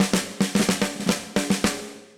Index of /musicradar/80s-heat-samples/110bpm
AM_MiliSnareB_110-02.wav